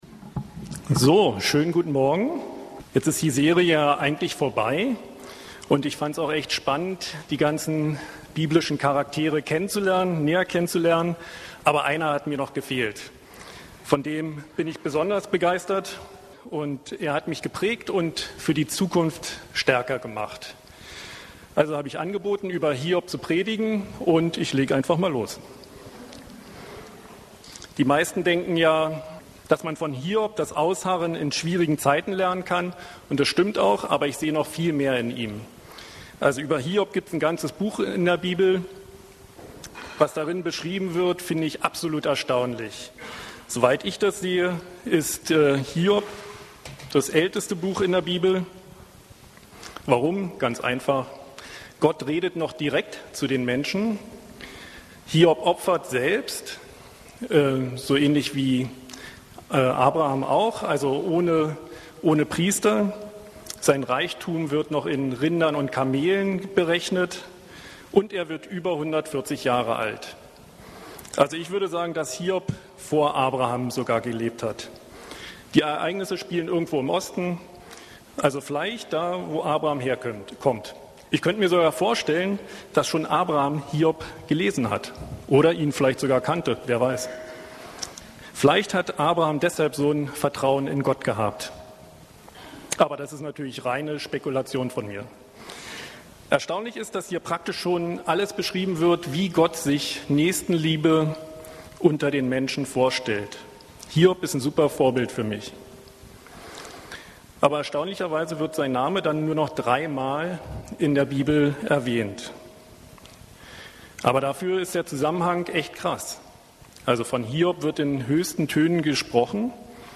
Predigtserie